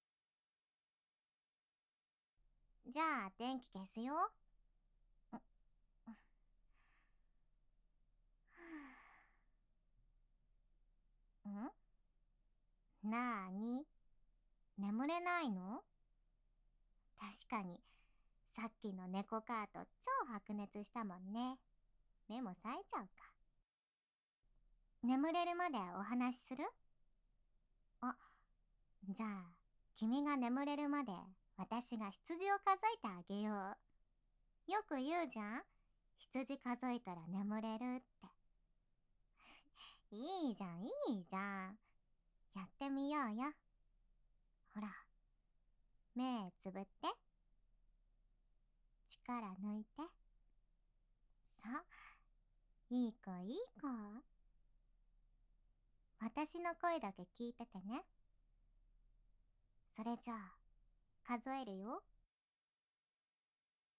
【睡眠導入1時間】ダウナー幼馴染が添い寝して羊を数えてくれる【甘々・癒し・安眠】 - ASMR Mirror